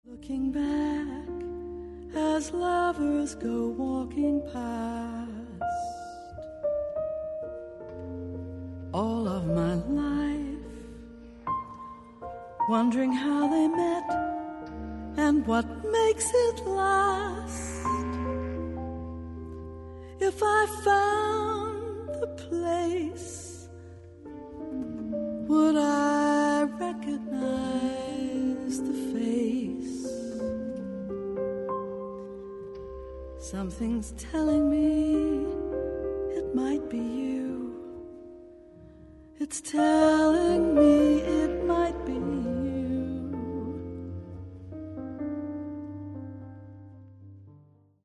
Recorded December 2012, Auditorio Radiotelevisione, Lugano
Piano
Sop. Sax, Bs Clt